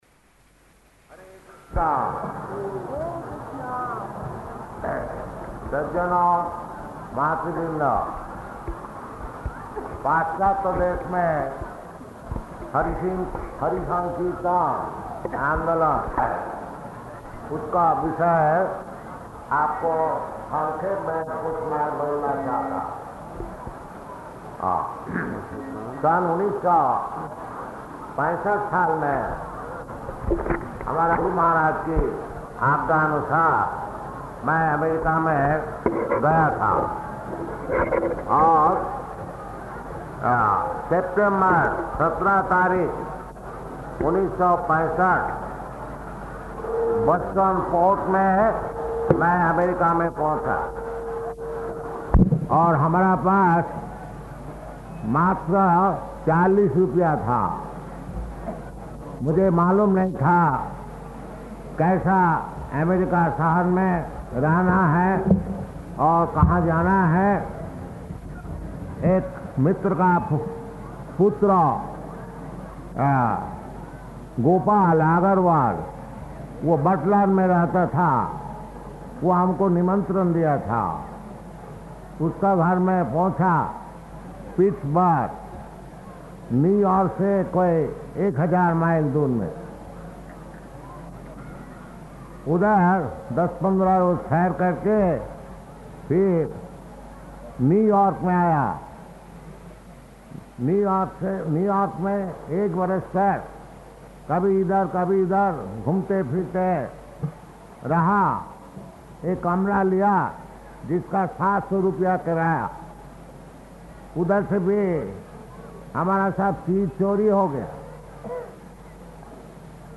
Lecture in Hindi
Lecture in Hindi --:-- --:-- Type: Lectures and Addresses Dated: December 30th 1970 Location: Surat Audio file: 701230LE-SURAT.mp3 Prabhupāda: Hare Kṛṣṇa. [Hindi] Lecture in Hindi Śrīmad-Bhāgavatam 6.1.45–50